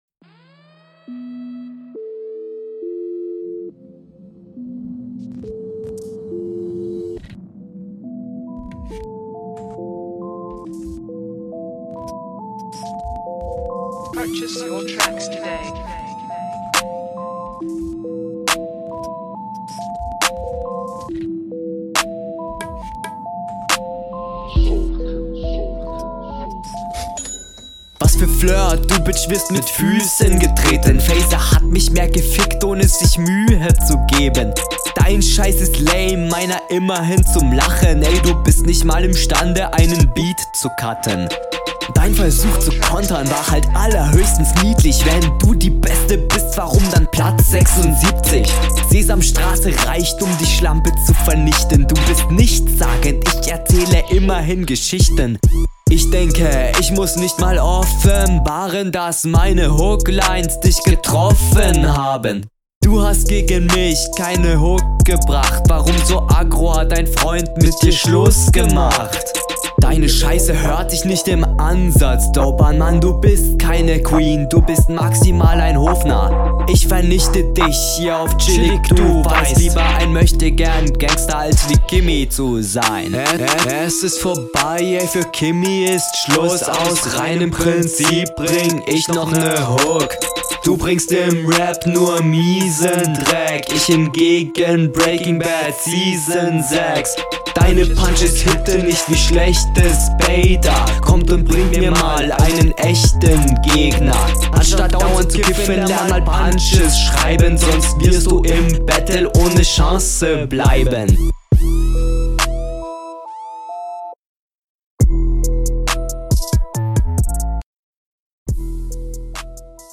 Flow: Bisschen unsicher, aber auf dem Takt.